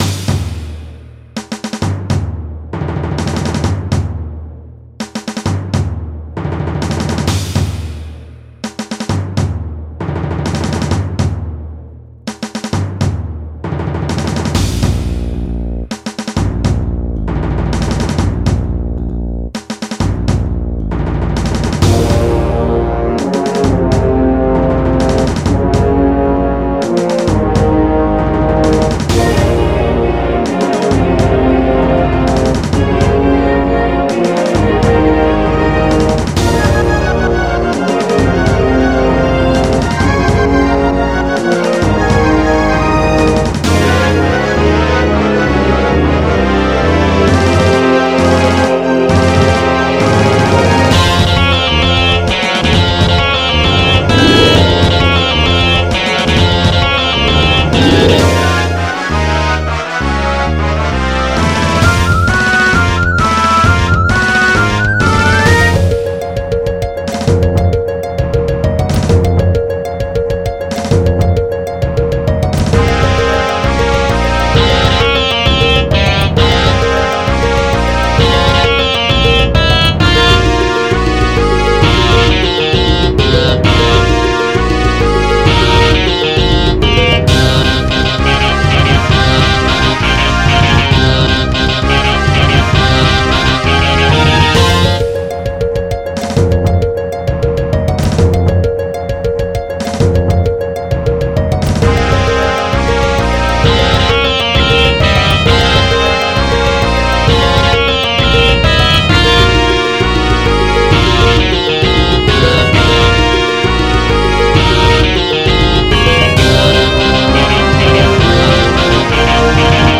Extended MIDI-Mix